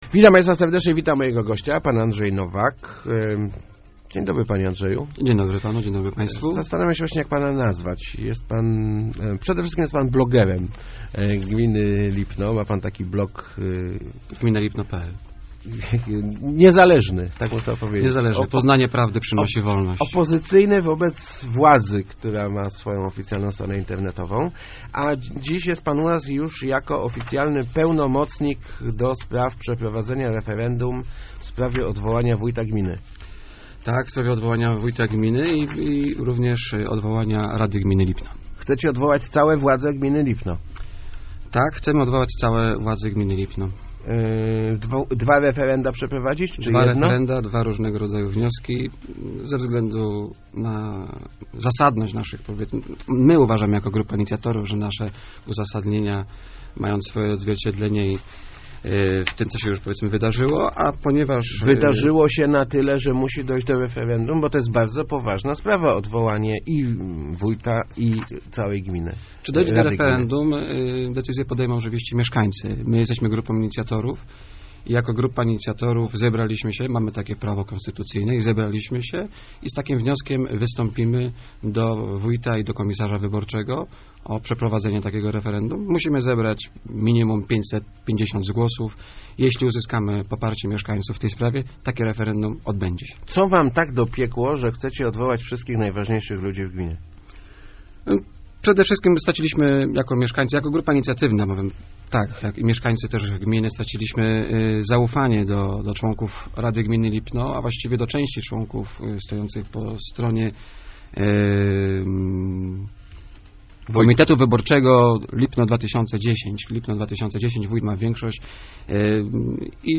w Rozmowach Elki